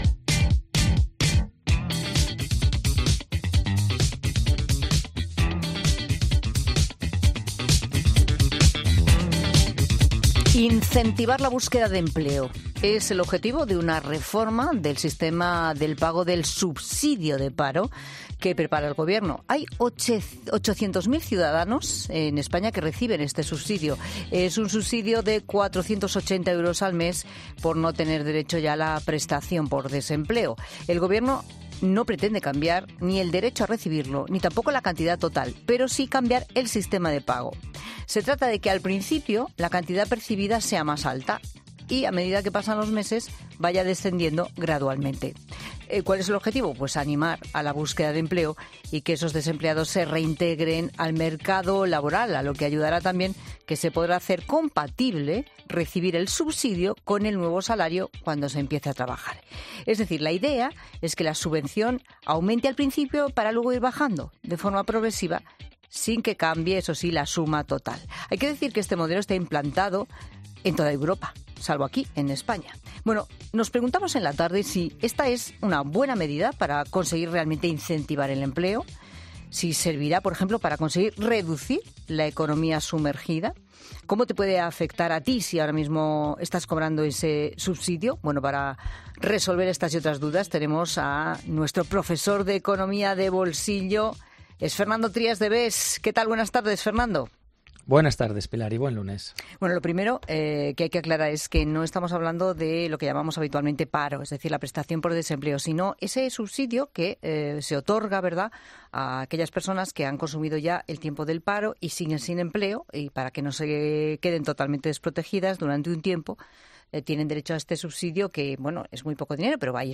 Escucha la sección de 'Economía de Bolsillo' de 'La Tarde' con Fernando Trías de Bes, economista y escritor